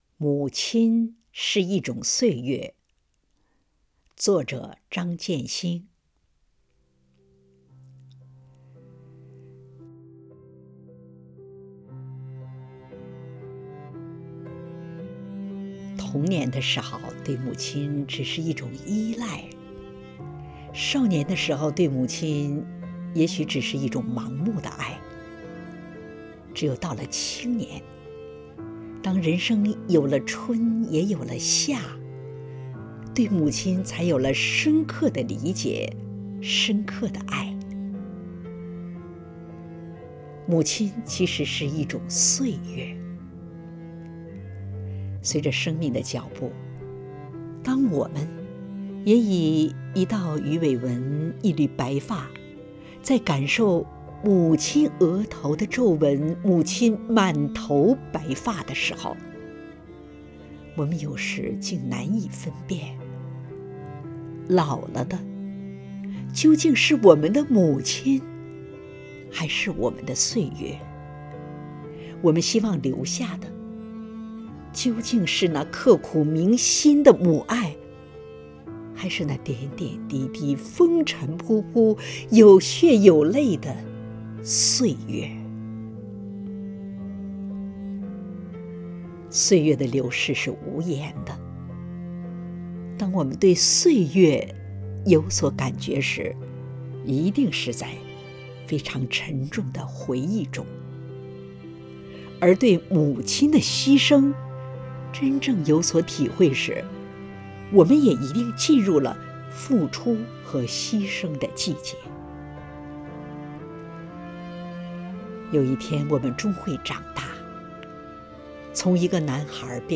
敬一丹朗诵《母亲是一种岁月》